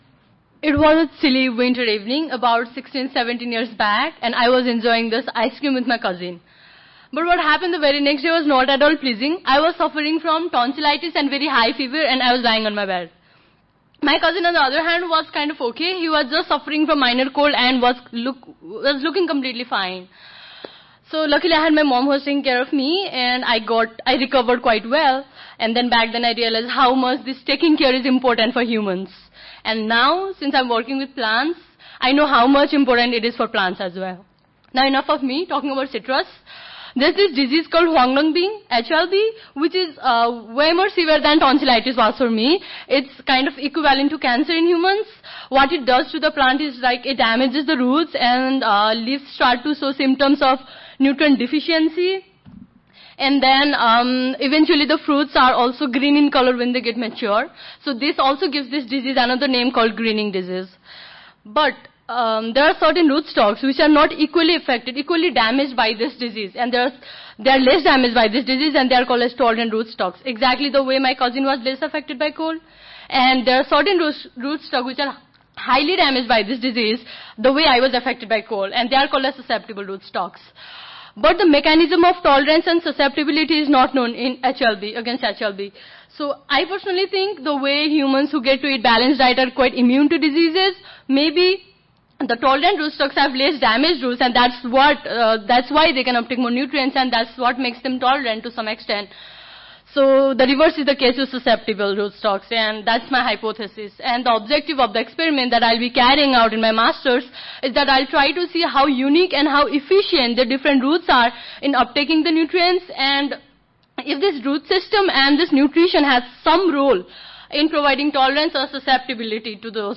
Scholars Ignite Competition
Georgetown West (Washington Hilton)
Audio File Recorded Presentation